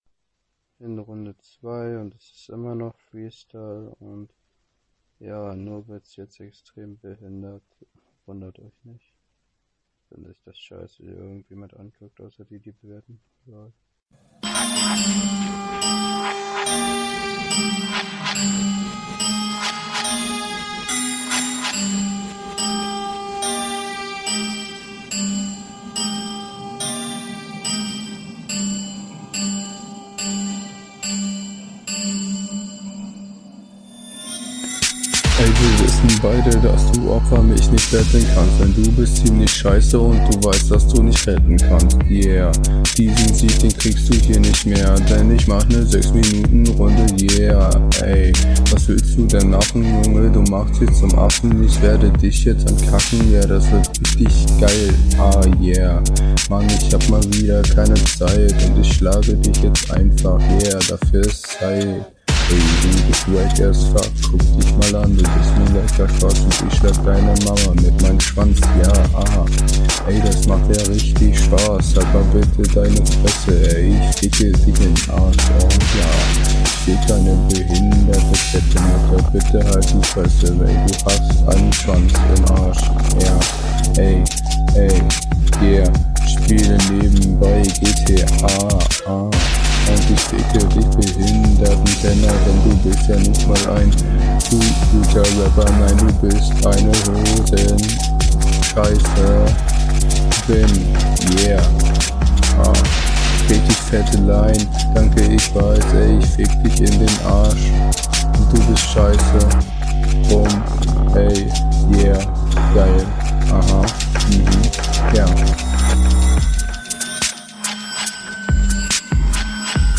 Flow: Du spielst kein bisschen mit den Patterns in dem Beat.